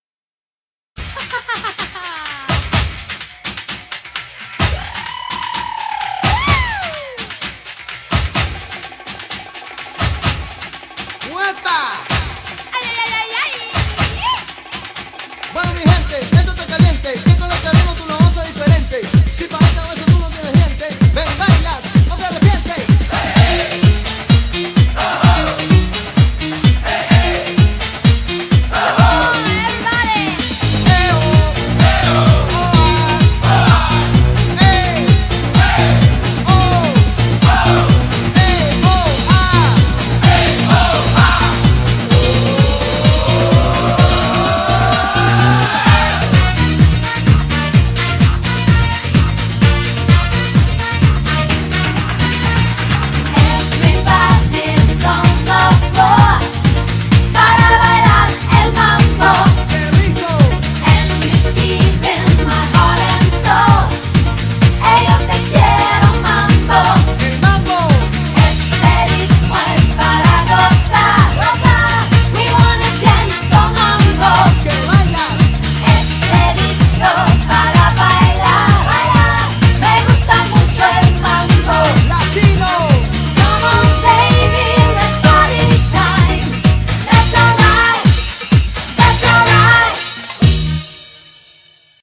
BPM128--1
Audio QualityMusic Cut
- Music from custom cut